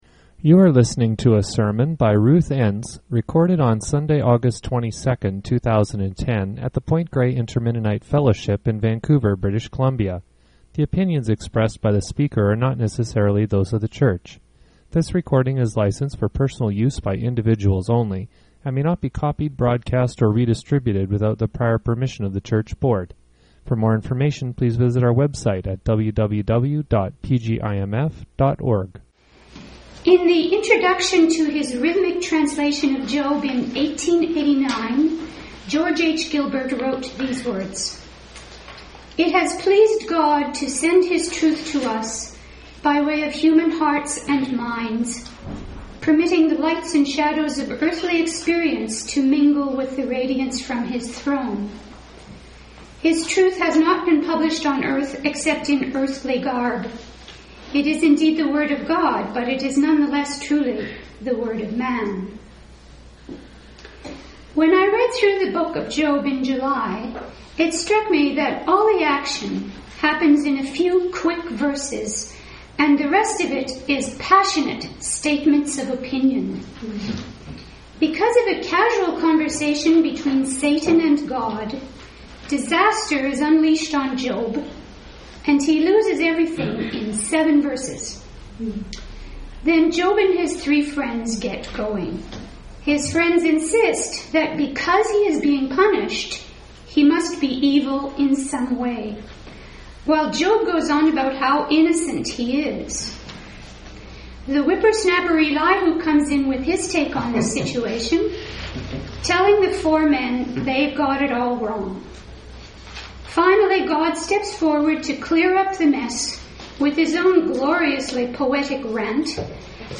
Labels: PGIMF sermon discussion